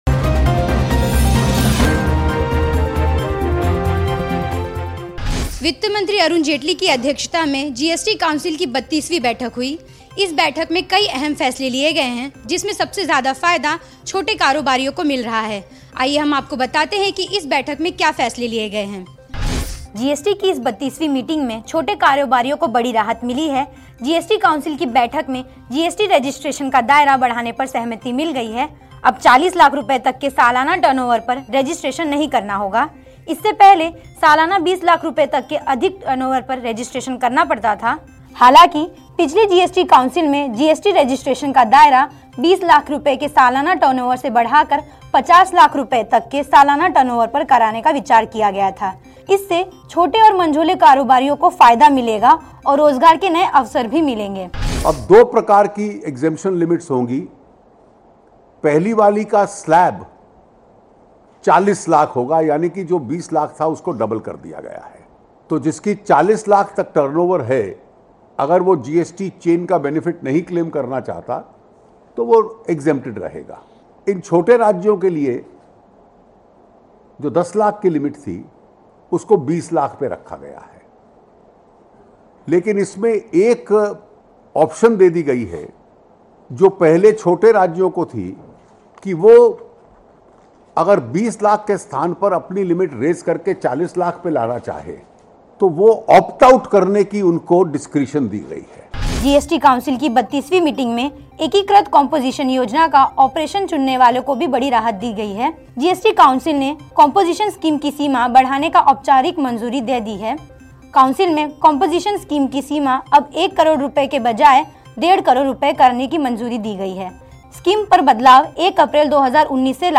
न्यूज़ रिपोर्ट - News Report Hindi / जानिए GST काउंसिल के 32वीं बैठक ने किसको दिया फायदा